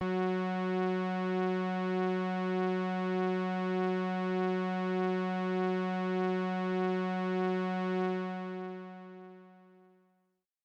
标签： midivelocity80 F4 midinote66 AkaiAX80 synthesizer singlenote multisample
声道立体声